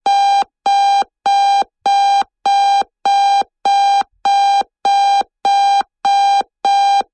Alarm clock rings. Clock, Alarm Ring, Alarm Clock Beep, Alarm Clock